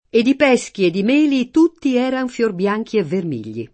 ed i p$Ski ed i m%li t2tti $ran fLor bL#jki e vverm&l’l’i] (Carducci) — sim. il top. il M. (Tosc.) — cfr. melo cotogno